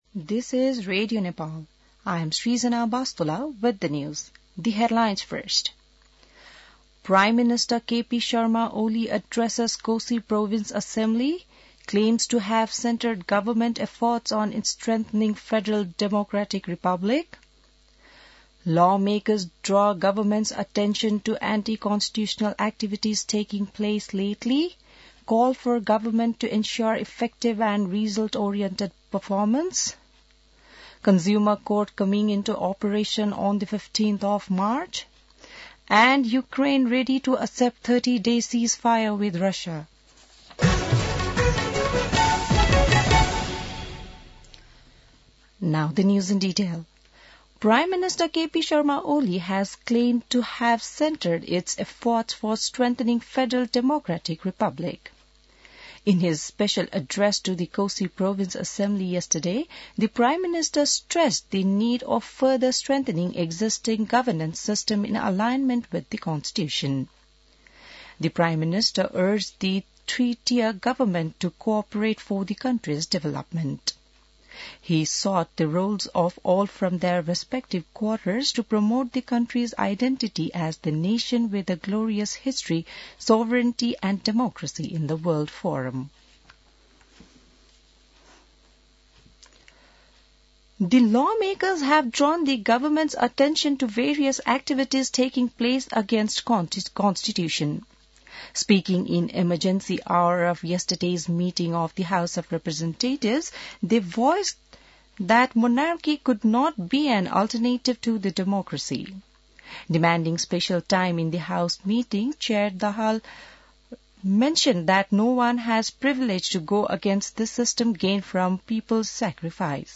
An online outlet of Nepal's national radio broadcaster
बिहान ८ बजेको अङ्ग्रेजी समाचार : २९ फागुन , २०८१